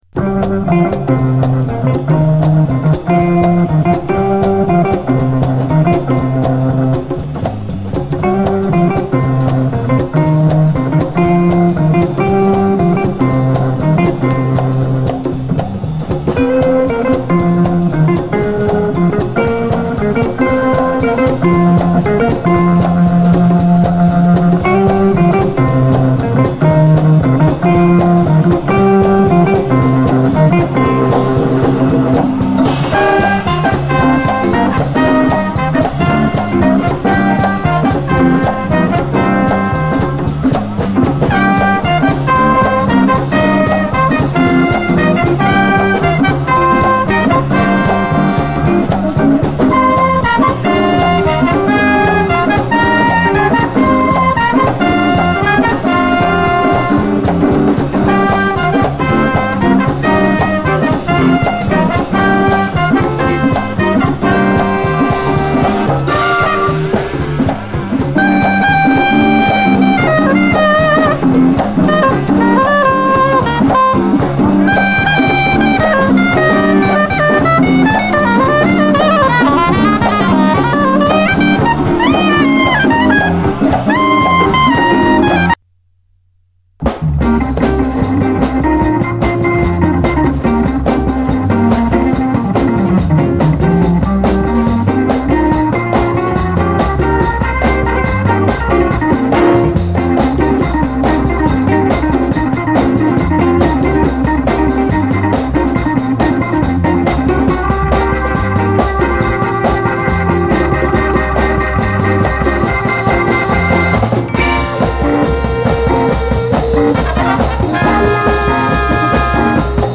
JAZZ FUNK / SOUL JAZZ, JAZZ
男臭さをまき散らして疾走するオルガン・ジャズ・ファンク
ドラム・ブレイクも収録！
こちらのライヴ録音盤も全編カッコいいレアグルーヴ名盤！男臭さをまき散らして疾走するソウル・ジャズ〜ジャズ・ファンク